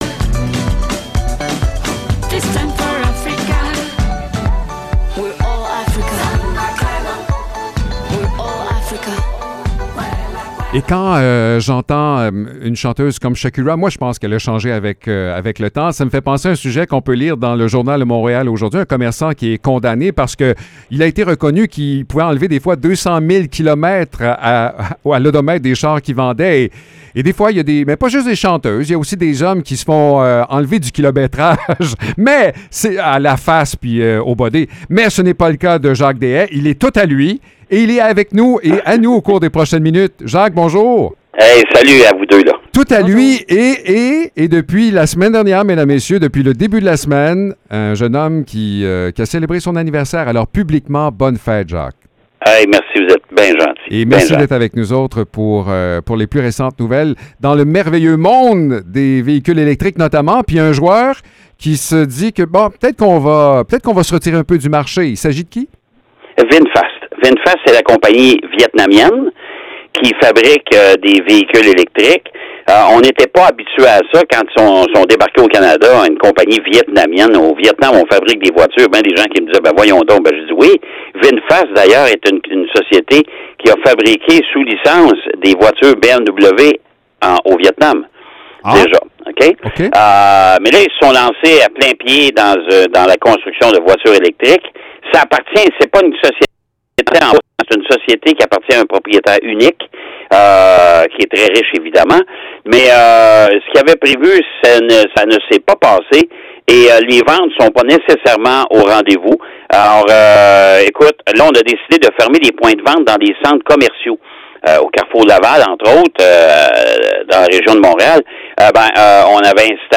Chronique automobile